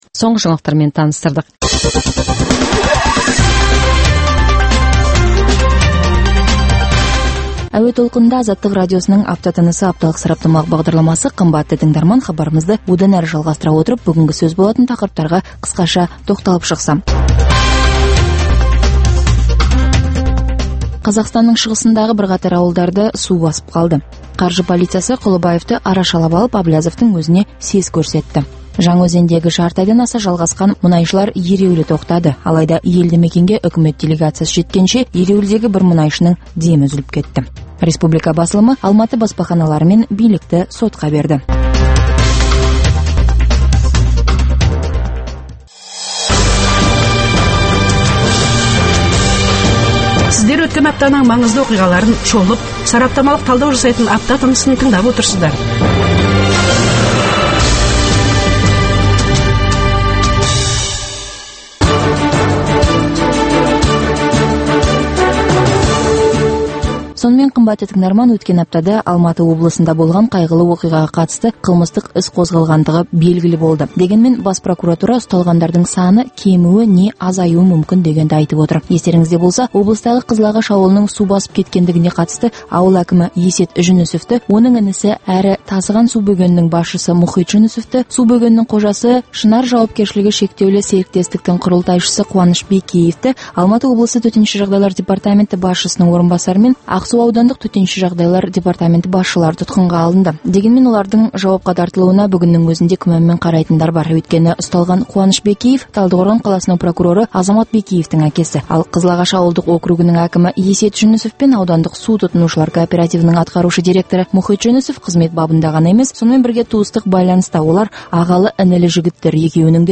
Күнделікті бағдарлама – Күнделікті Қазақстанда және әлемде орын алып жатқан саяси-әлеуметтік, экономикалық жаңалықтар бойынша жедел әзірленген хабарлар топтамасы.